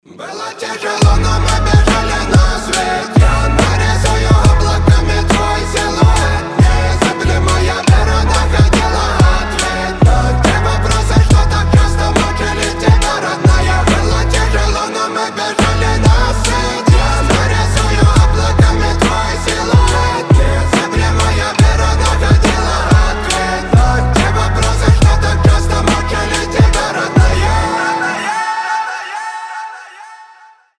Рэп рингтоны
Хип-хоп